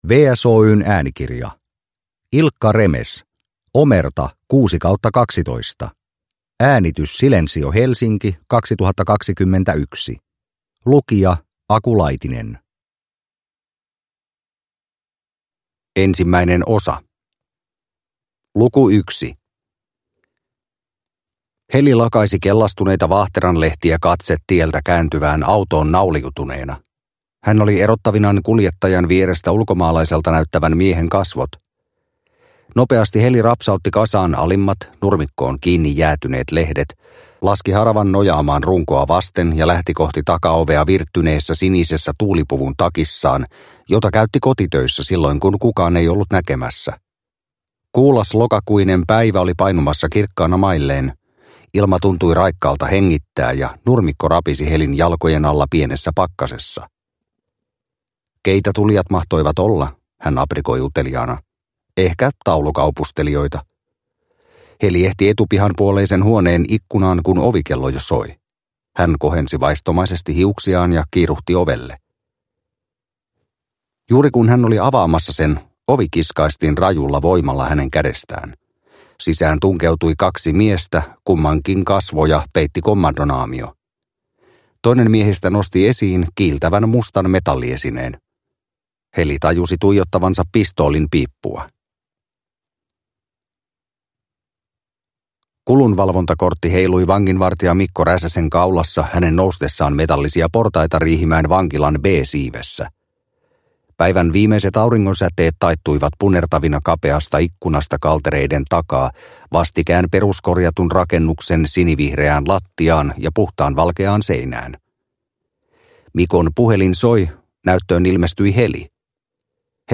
Omerta 6/12 – Ljudbok – Laddas ner